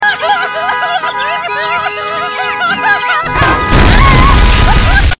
Traffic Jam Sound Effects Free Download